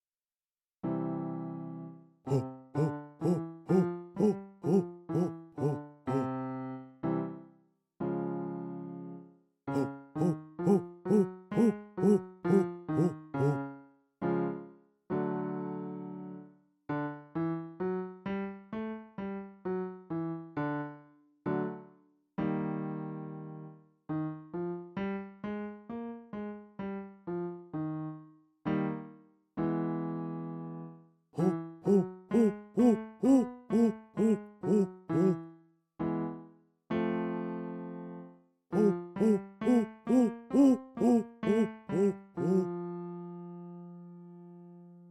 息漏れのある地声／チェストボイス／胸声の練習方法
音量注意！
1. 発音は「ホ」
2. 音域は～C4
3. 音量は徐々に大きくなる
practice-chestvoice-01.mp3